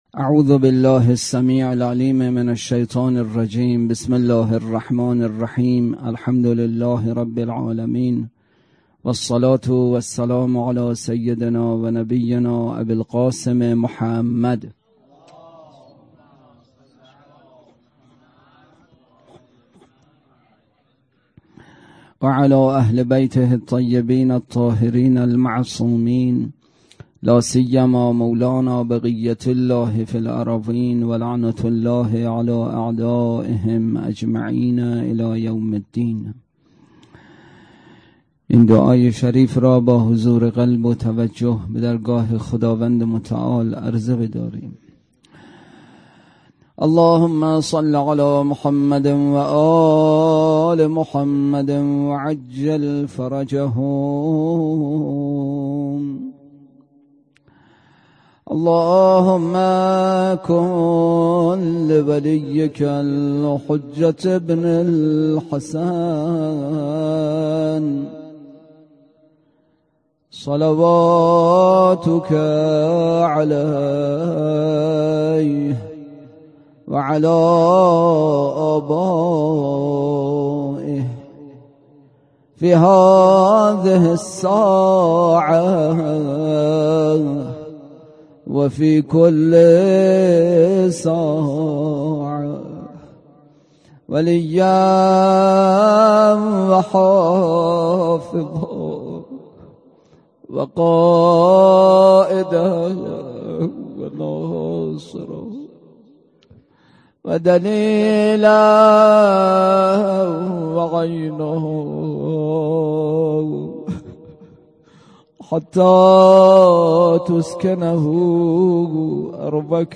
سخنرانی
احکام: تعریف مؤونه، موارد استثناء بحث اصلی: شرح خطبه‌ی متقین: فَقَسَمَ بَیْنَهُمْ مَعَایِشَهُمْ وَ وَضَعَهُمْ مِنَ الدُّنْیَا مَوَاضِعَهُم. روضه: امام رضا(ع)